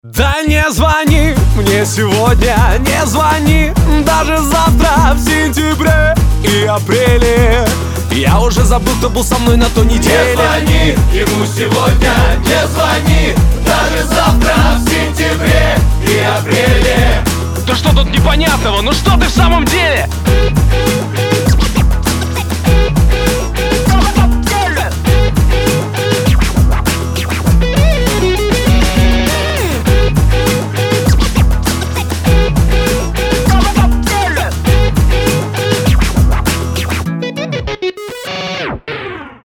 • Качество: 320, Stereo
Хип-хоп
Rap-rock
веселые